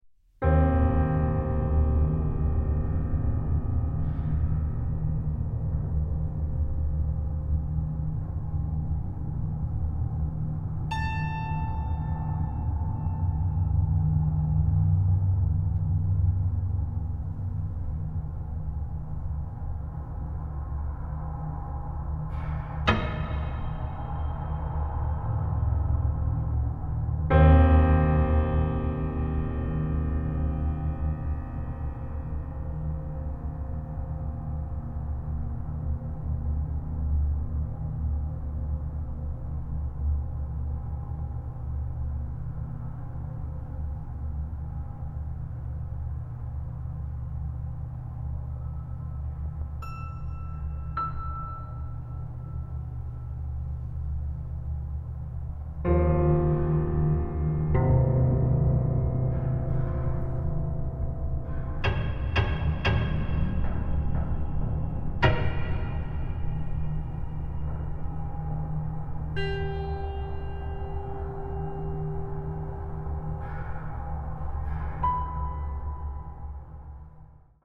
for solo piano, transducers, and field recordings